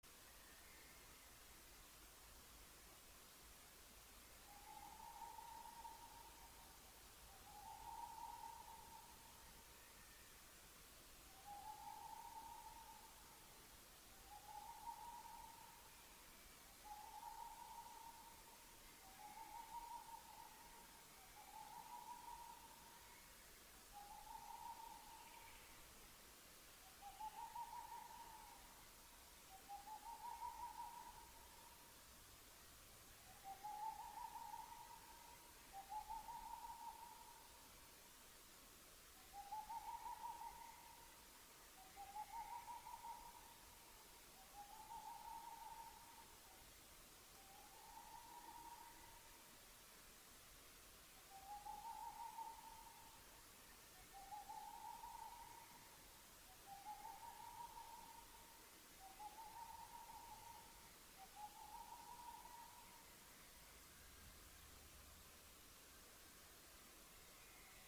Bikšainais apogs, Aegolius funereus
Administratīvā teritorijaCēsu novads
StatussDzied ligzdošanai piemērotā biotopā (D)